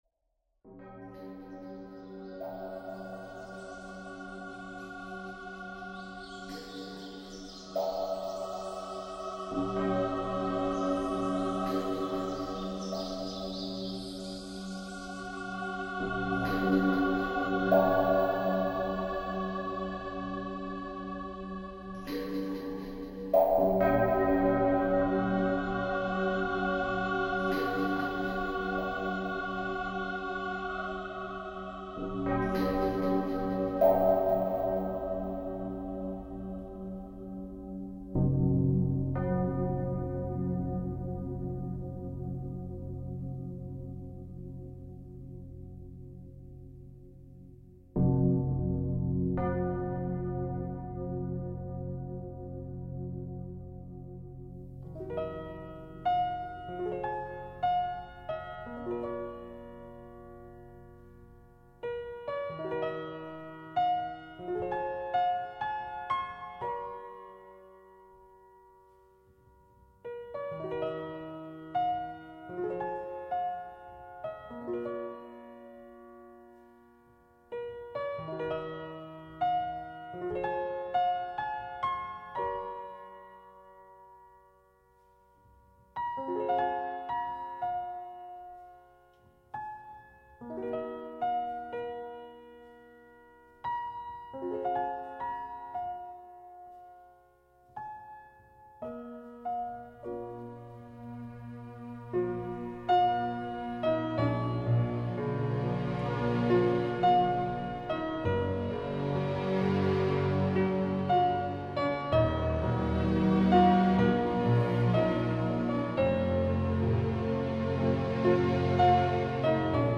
你可以在第一首的高潮听到左面是小提琴，右面是琵琶，而二者相互辉映。